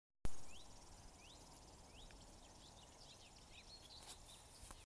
речной сверчок, Locustella fluviatilis
Administratīvā teritorijaRīga
СтатусПоёт
ПримечанияSaklausāms fonā.